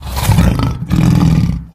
izlome_hit_2.ogg